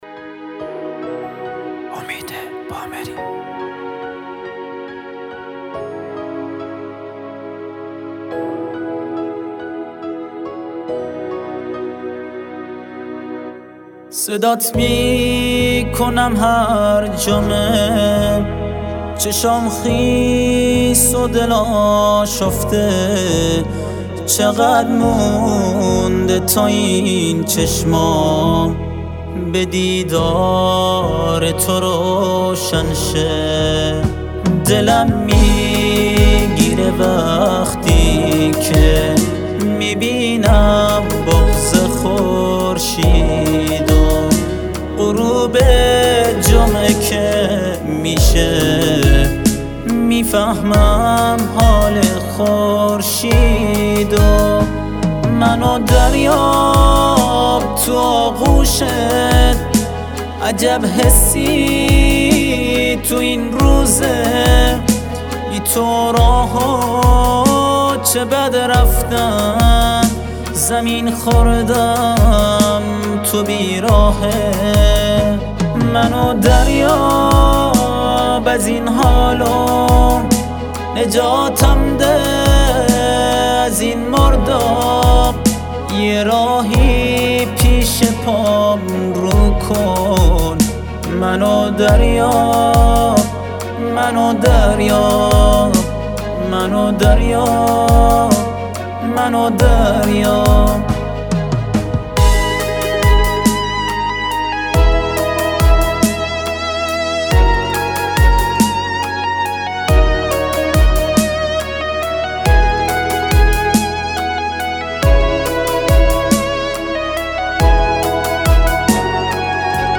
آهنگ بلوچی